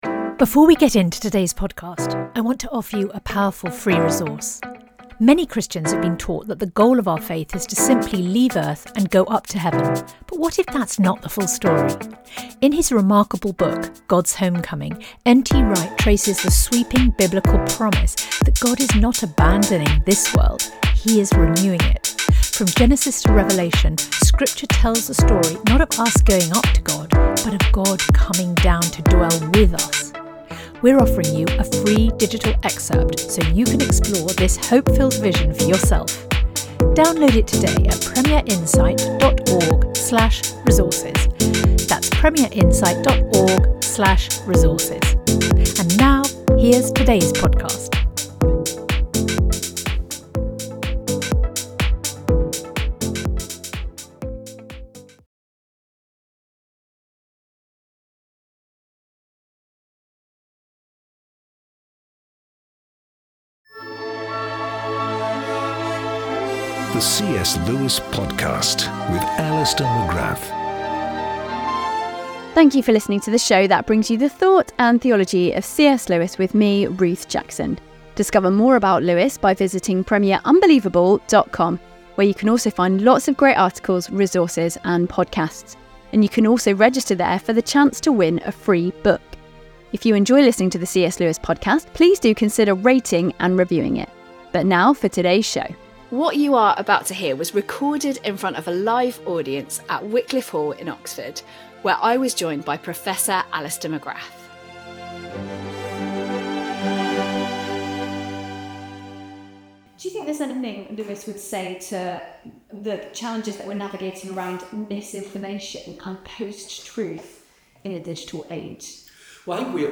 recorded in front of an audience. The programme was made in partnership with the New Renaissance Project at Wycliffe Hall, University of Oxford. The conversation explores today’s major questions around AI, what it means to be human in today’s world, and the advancements happening in modern technology.